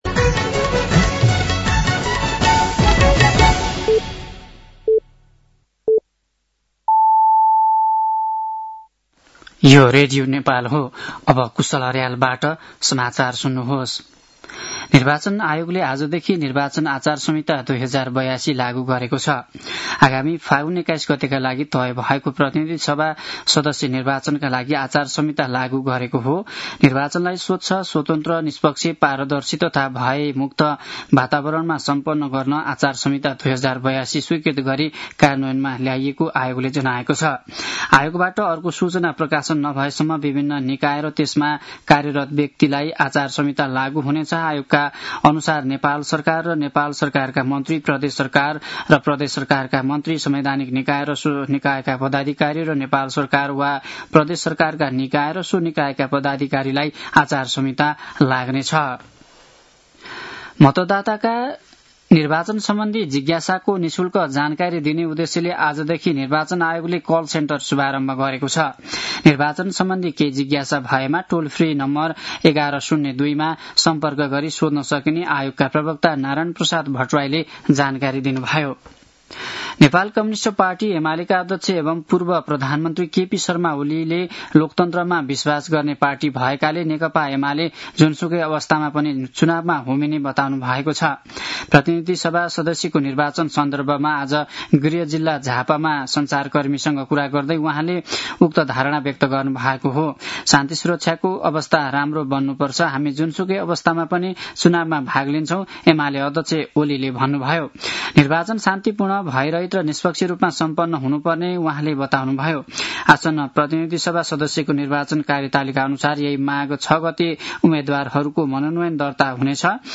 साँझ ५ बजेको नेपाली समाचार : ५ माघ , २०८२
5.-pm-nepali-news-1-8.mp3